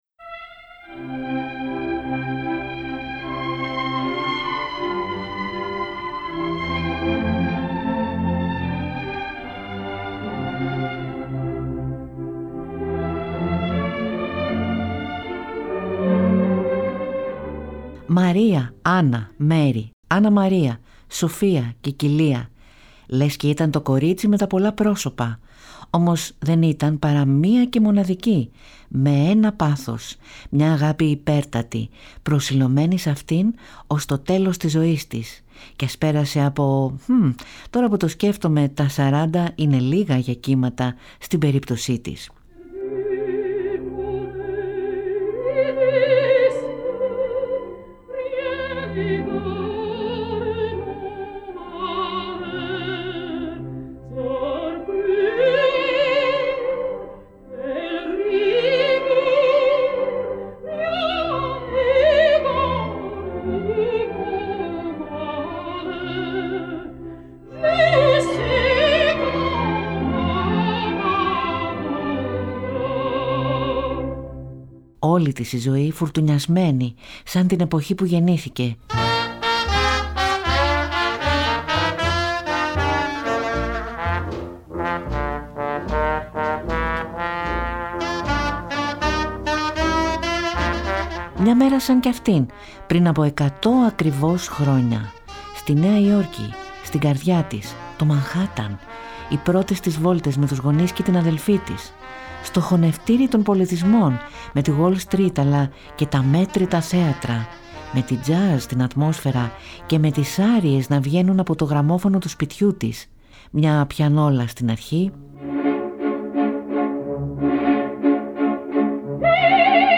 Ο Κατ μαθαίνει από τον Ευτύχη και μάς αφηγείται κι από μια ιστορία που διάβασε και πάντα την συνδέει με ένα αγαπημένο τραγούδι.